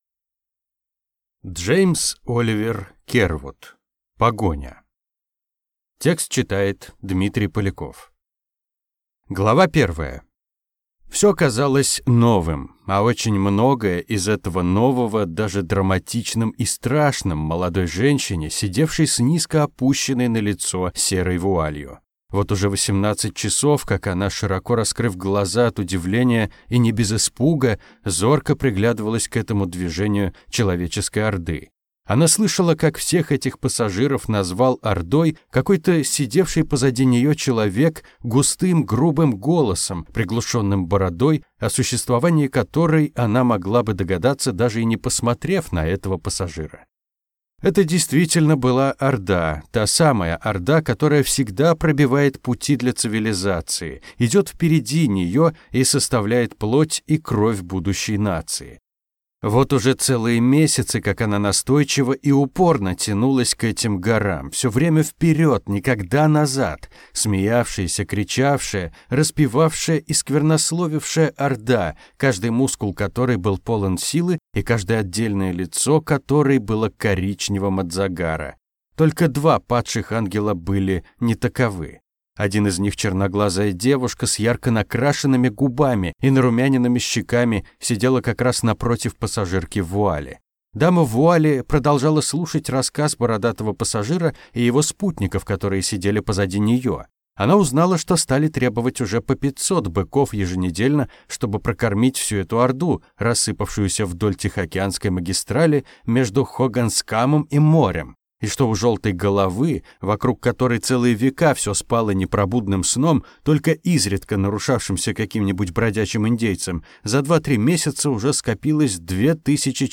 Аудиокнига Погоня | Библиотека аудиокниг